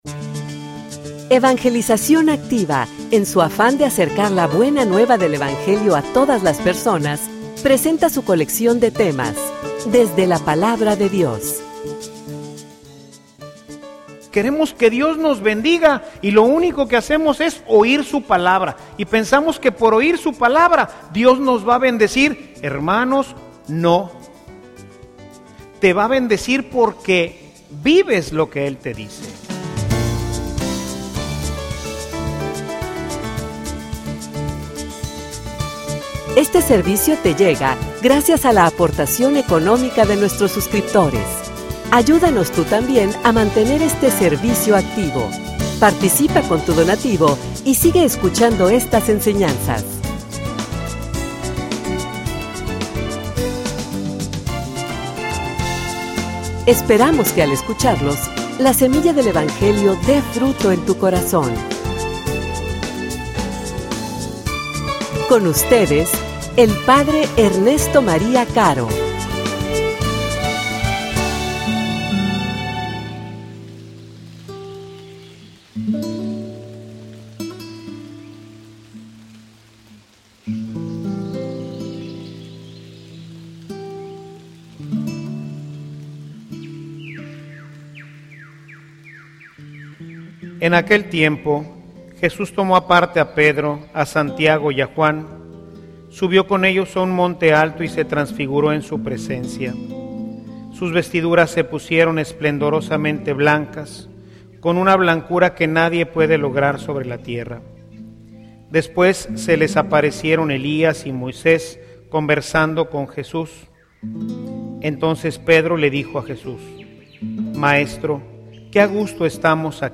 homilia_Por_haber_escuchado_y_obedecido.mp3